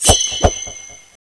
draw.wav